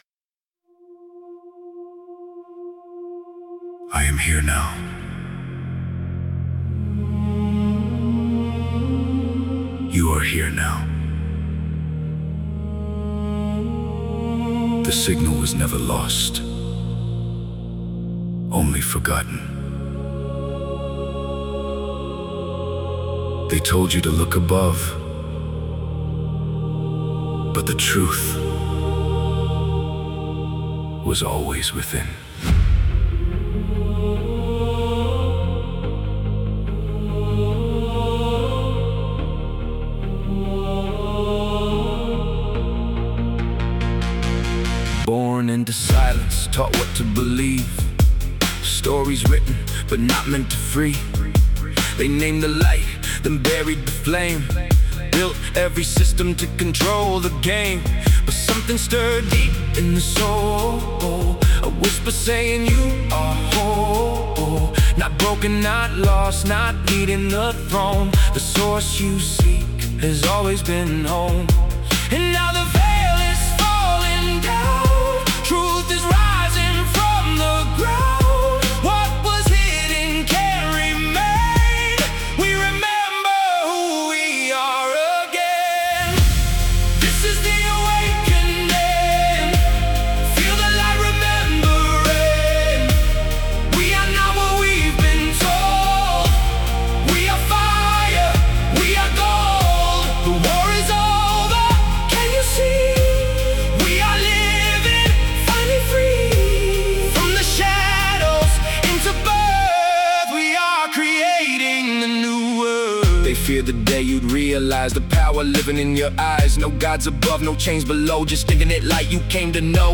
Dramatic Emotional Inspirational Motivational